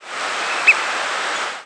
Olive-sided Flycatcher Contopus cooperi
Flight call description A short "pip" note may occasionally be used as a flight call.
Perched adult on breeding grounds.